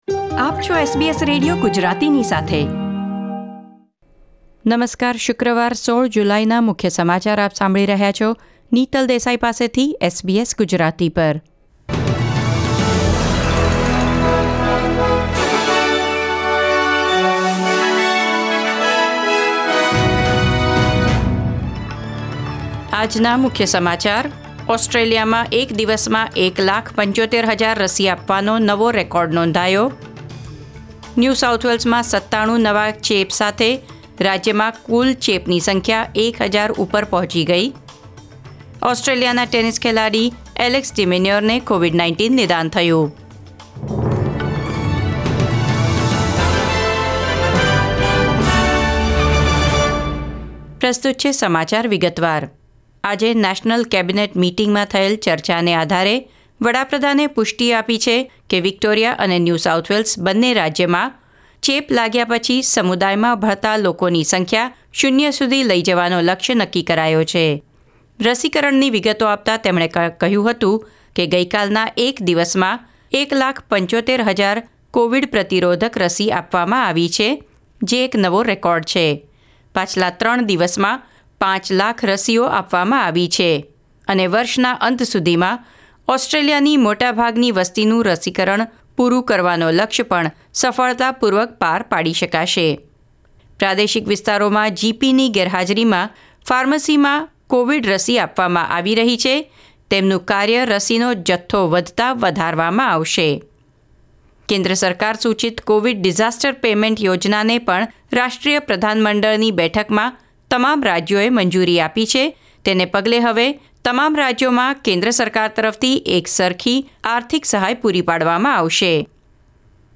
SBS Gujarati News Bulletin 16 July 2021